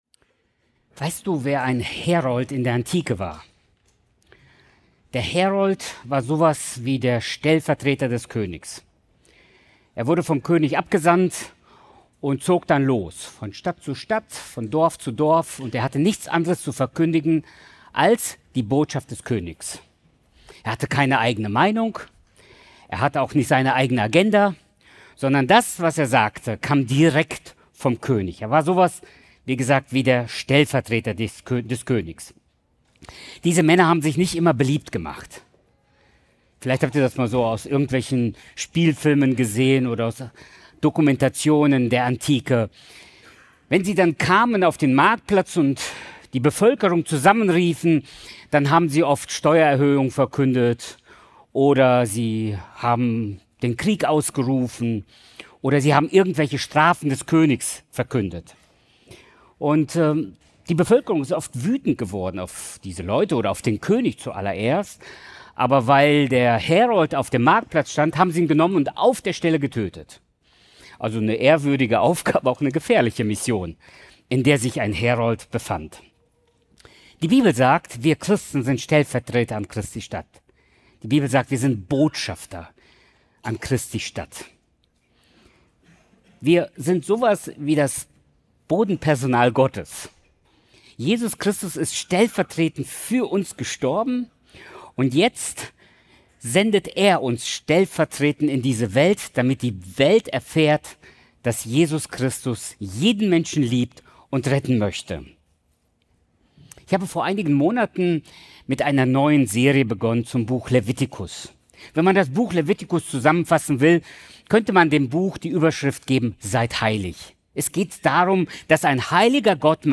März 2026 Predigt-Reihe: Seid heilig! - Das Buch Levitikus